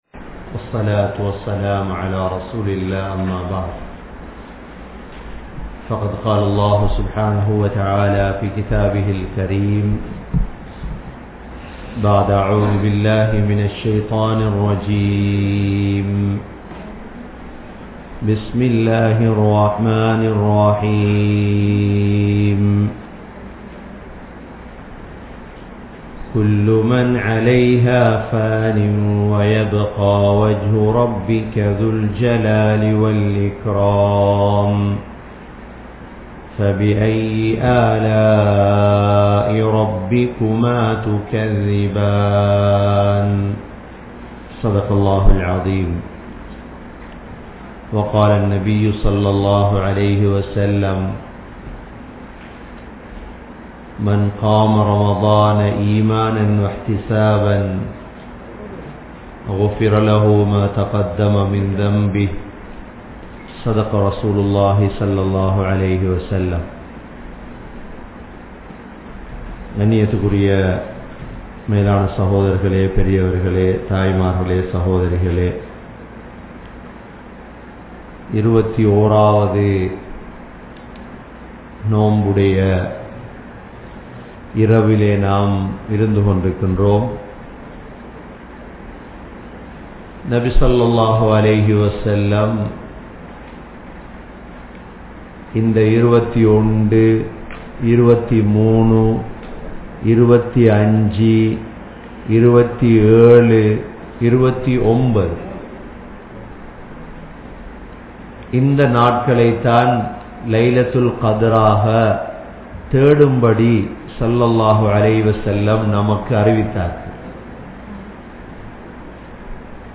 Ihthikaaf(Part 02) (இஃதிகாப்) | Audio Bayans | All Ceylon Muslim Youth Community | Addalaichenai
Canada, Toronto, Thaqwa Masjidh